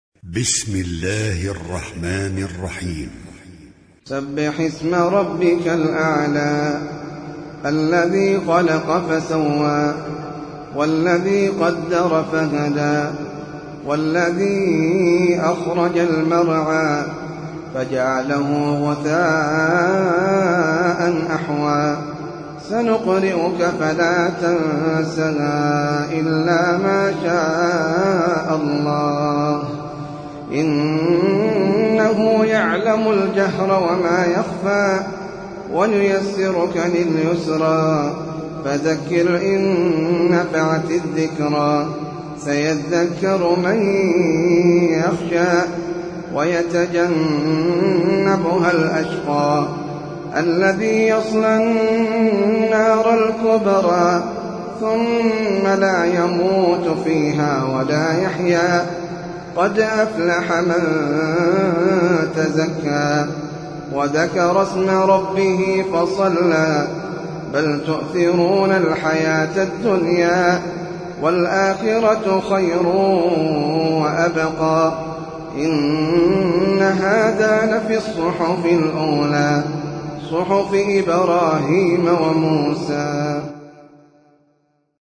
سورة الأعلى - المصحف المرتل (برواية حفص عن عاصم)
جودة عالية